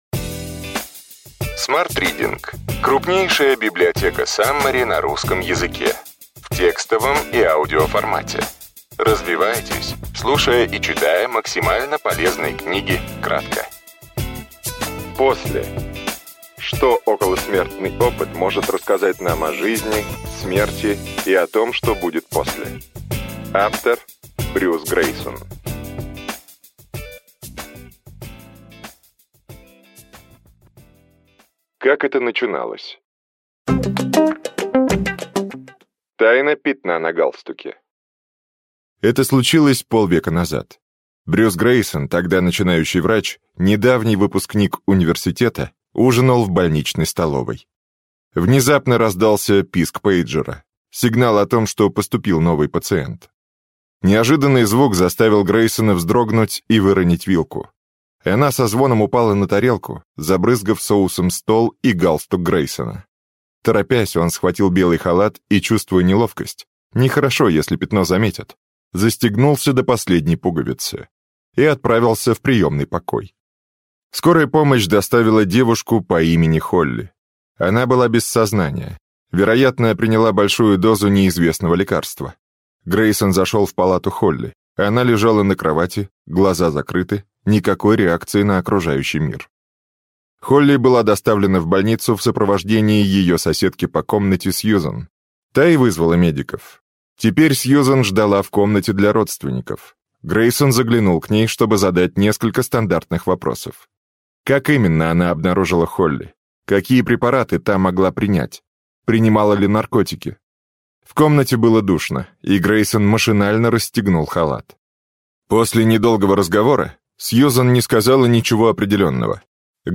Аудиокнига После. Что околосмертный опыт может рассказать нам о жизни, смерти и том, что будет после.